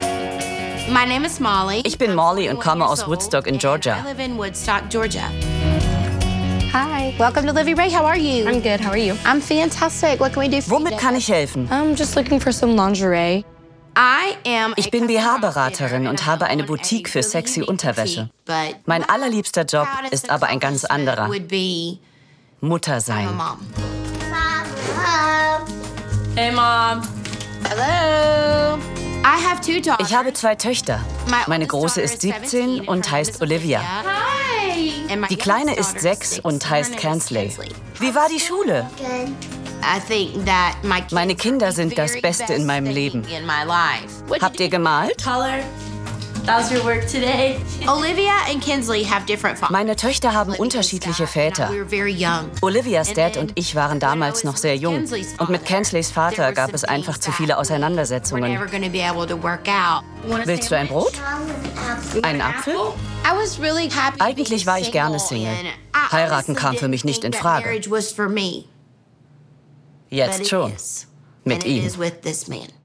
Voice-over
Trash-TV, amerikanisch, BH-Beraterin